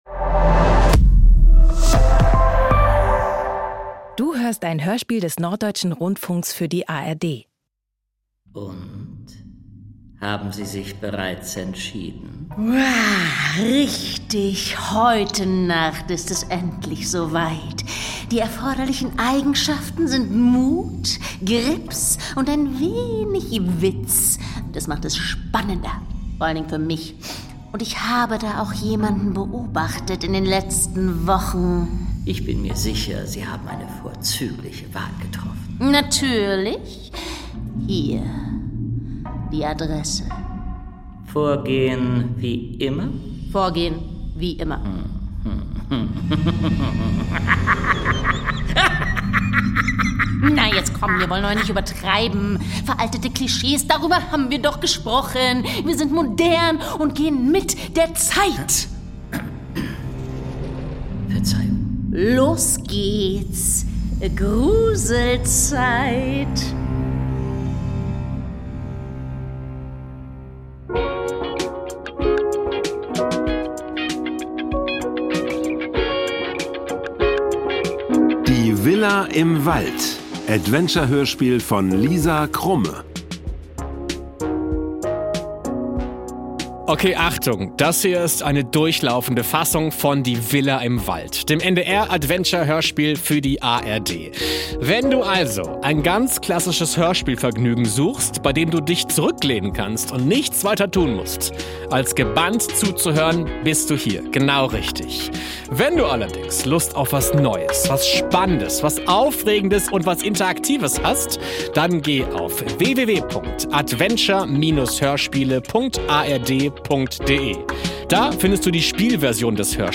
Die Villa im Wald. Ein interaktives Kinder-Abenteuer-Hörspiel – Podcast